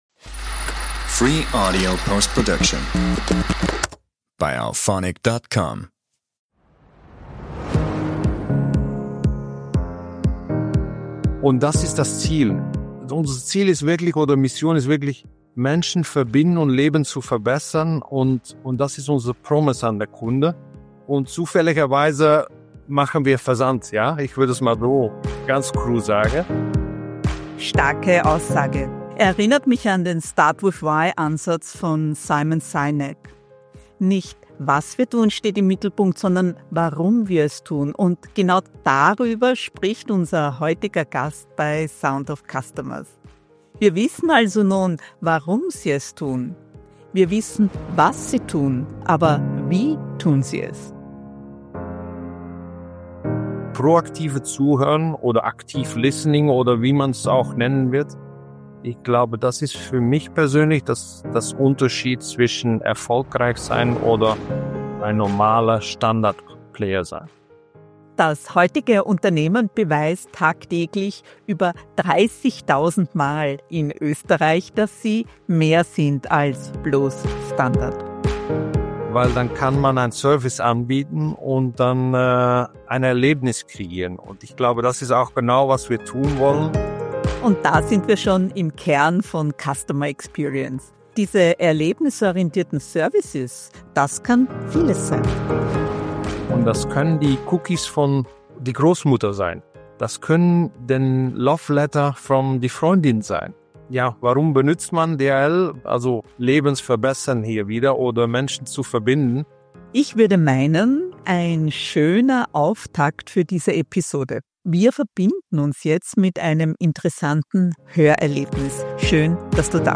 Customer Experience mit Turbo: der DHL Express Roadrunner Ein Gespräch